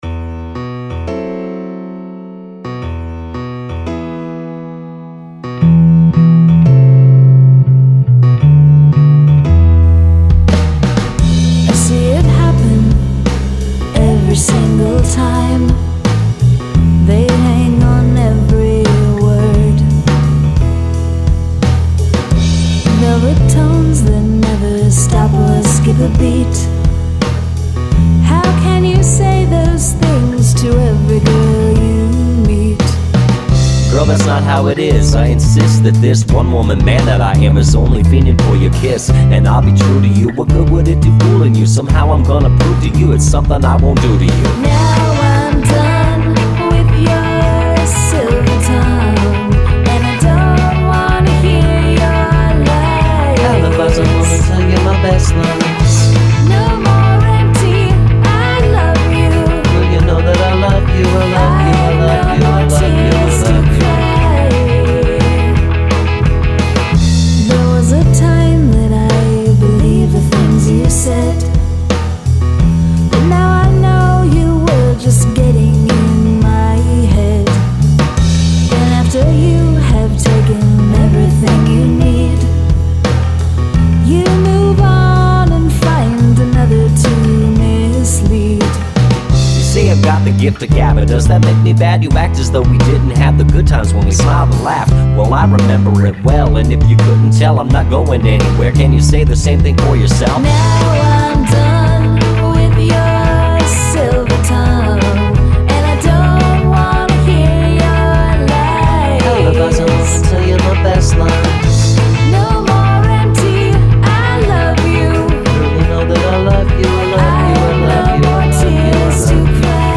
Guest Rap